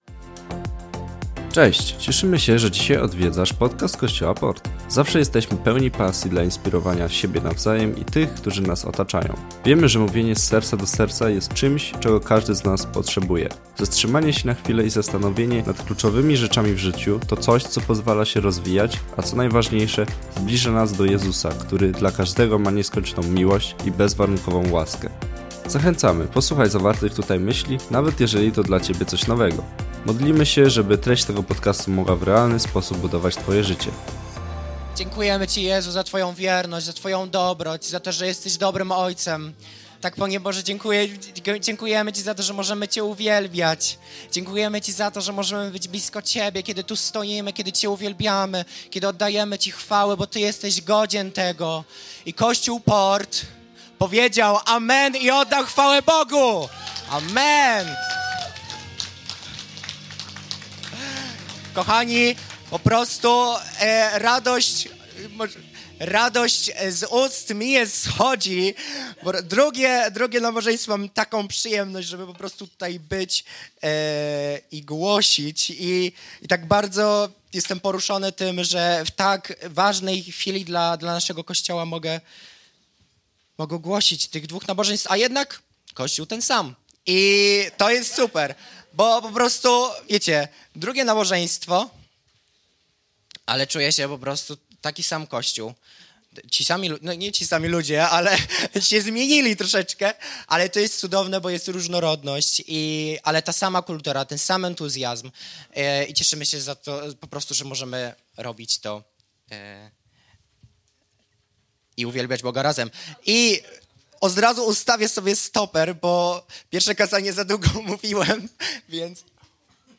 Życie, które sprawia, że inni rozkwitają jest możliwe. Posłuchaj kazania